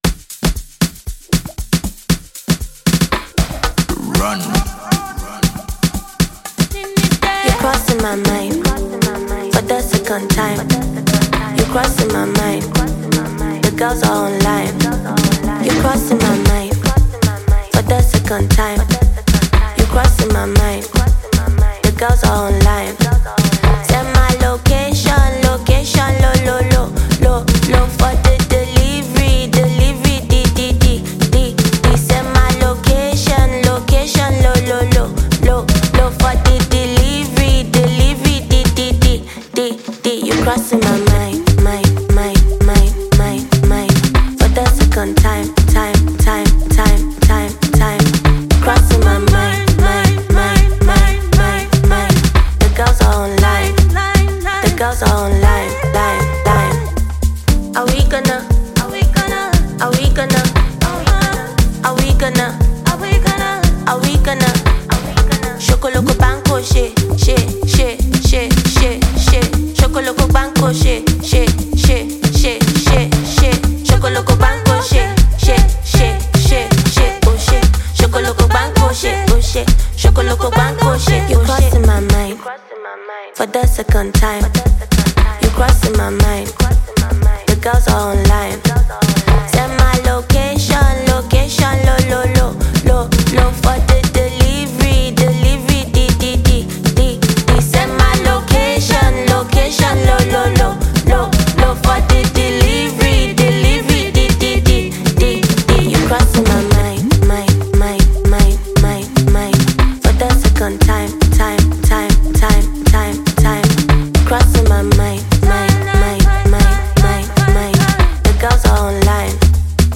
Nigerian fast rising songstress
afrohouse